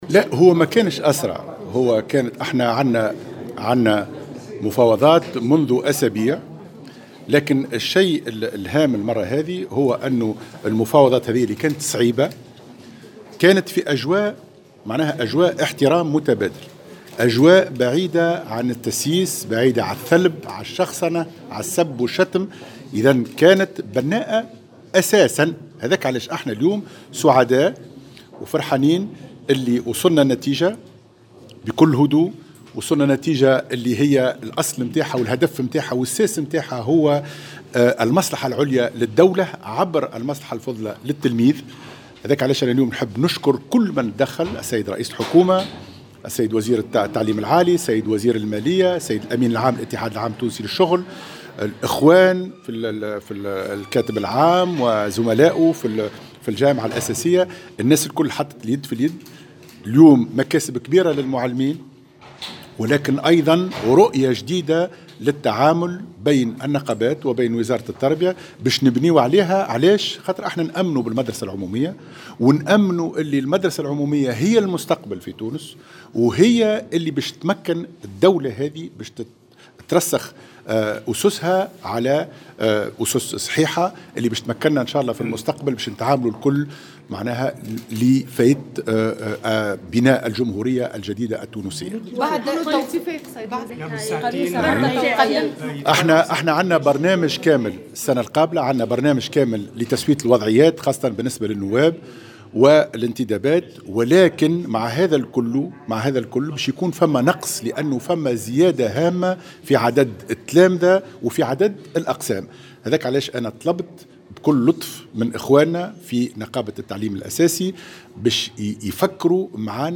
وأوضح في تصريح لمراسل "الجوهرة أف أم" أن وزارته ضبطت برنامجا كاملا بداية من السنة المقبلة لتسوية وضعيات النّواب على امتداد السنوات الثلاث القادمة.